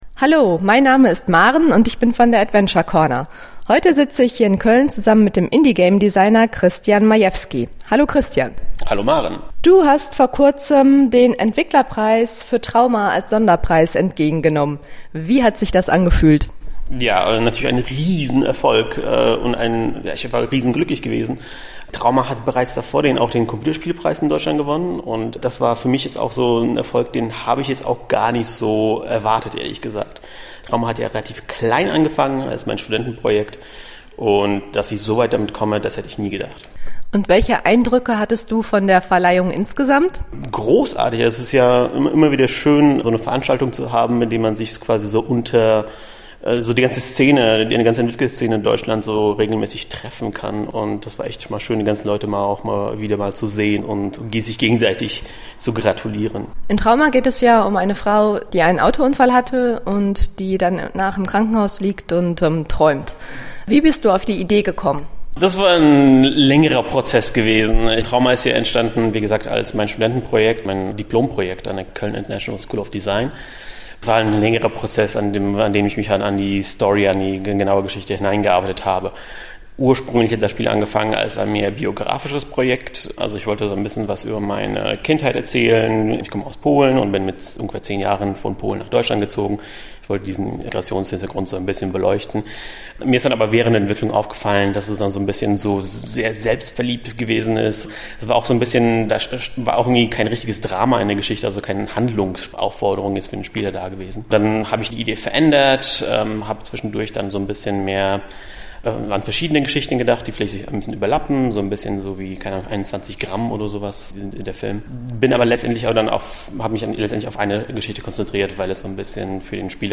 Schlagwort: Interviews